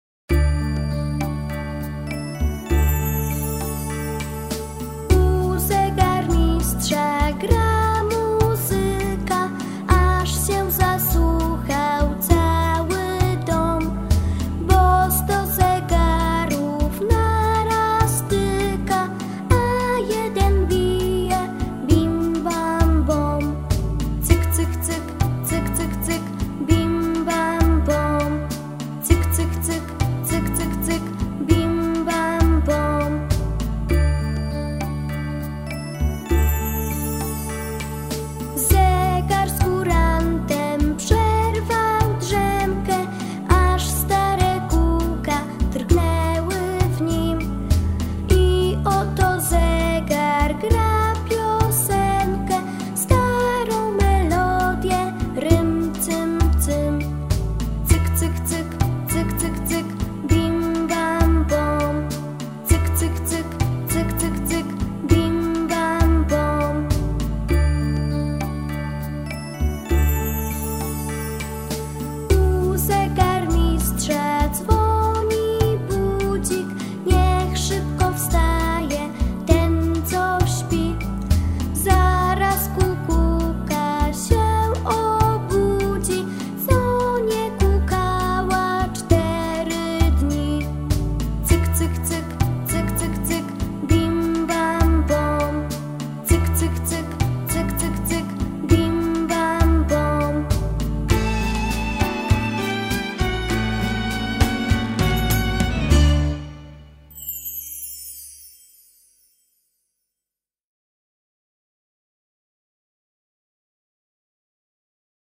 wersji wokalnej i
Zegary_tekst_i_zapis_nutowy.pdf 0.09MB Piosenka rekrutacyjna "Zegary"- wokal